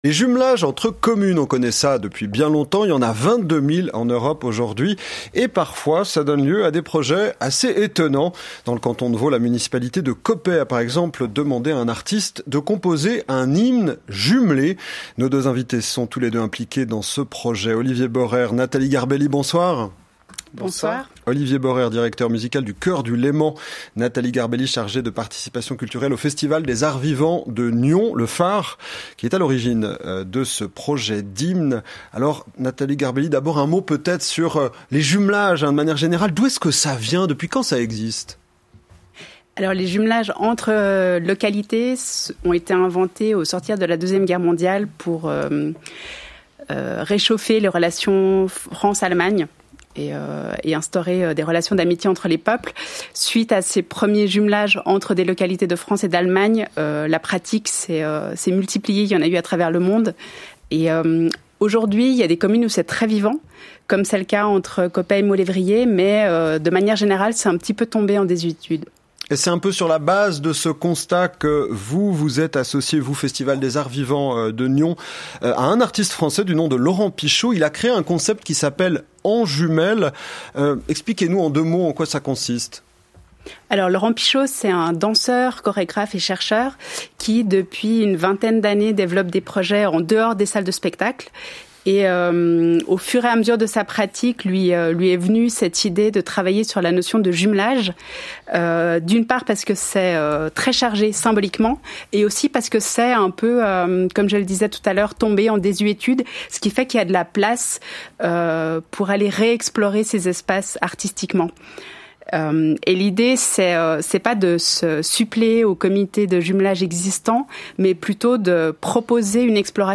A propose de l’hymne du jumelage de Coppet avec Maulévrier, retrouvez ici le balado de l’intervention de notre directeur sur l’émission Forum de la RTS le 13 avril dernier.